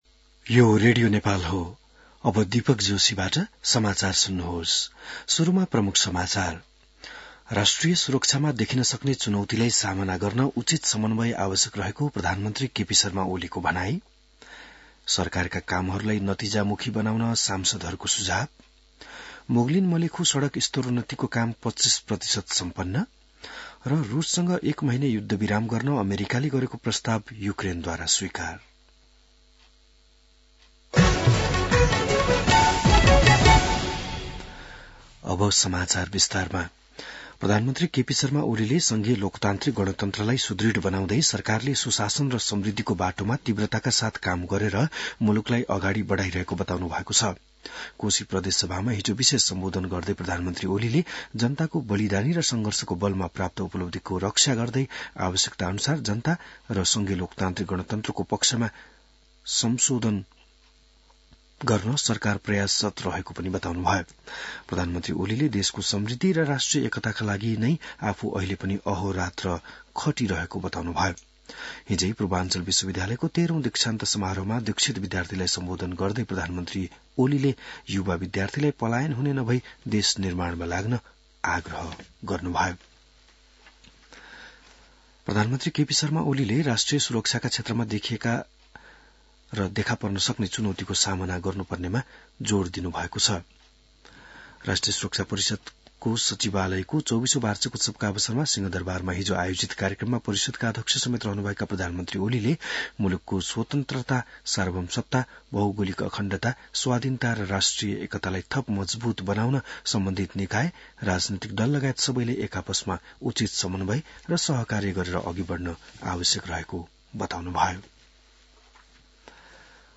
बिहान ९ बजेको नेपाली समाचार : २९ फागुन , २०८१